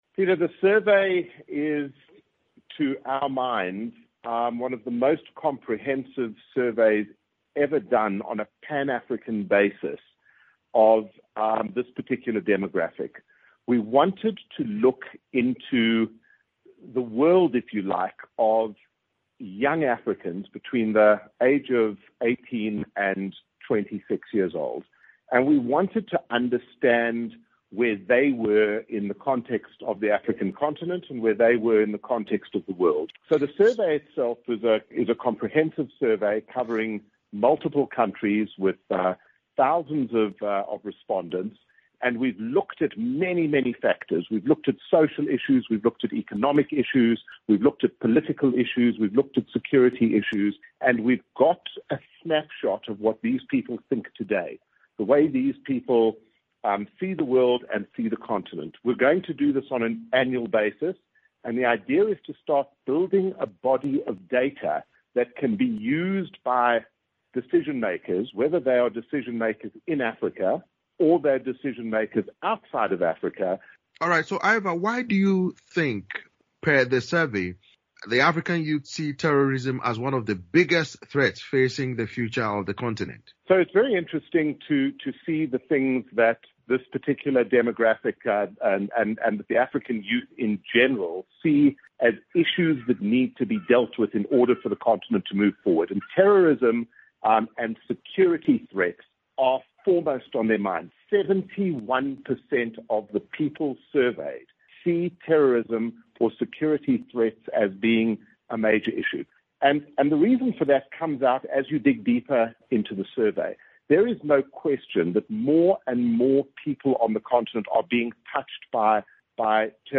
spoke to foundation chairman